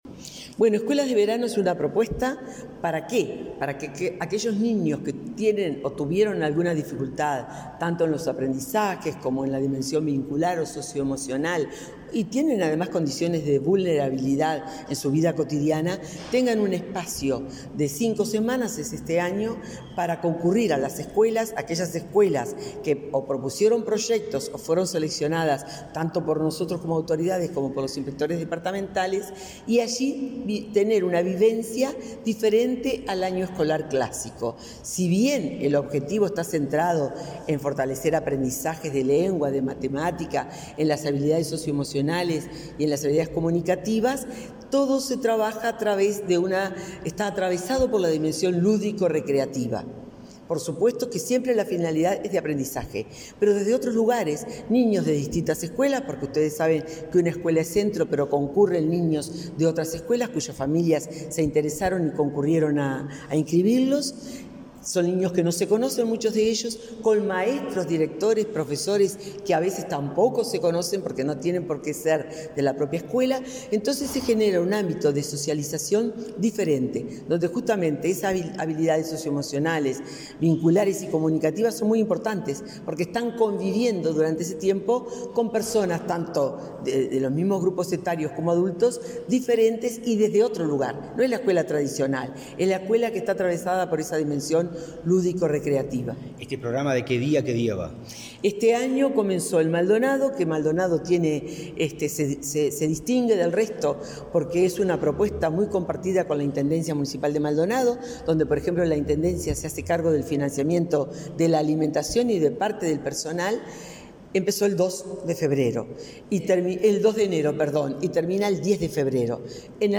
Entrevista a la directora general de Educación Inicial y Primaria, Graciela Fabeyro